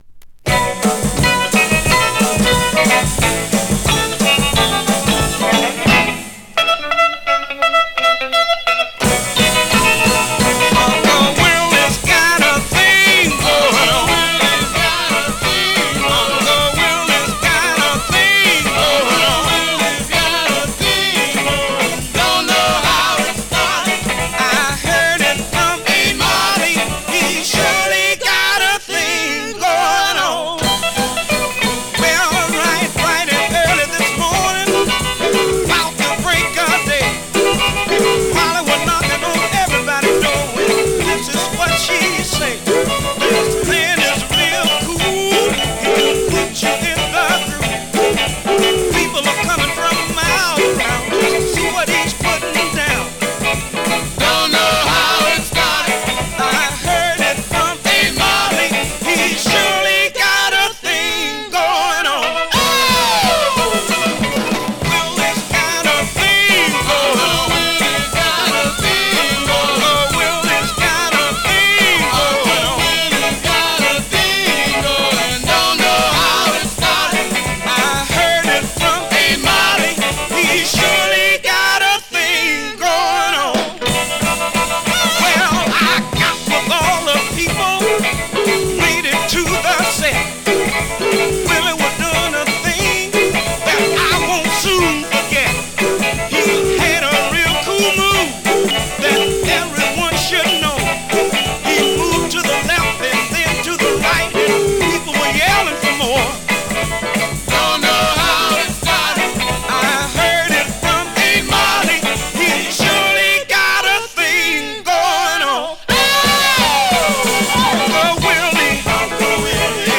Vinyl has a few light marks plays great .
Great classic mid-tempo Rnb / Mod dancer
R&B, MOD, POPCORN